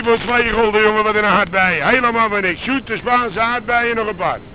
Man trying to sell strawberries on the Albert-Cuyp market,Amsterdam Januari 1995 Elevator ----- Next view ----- Previous view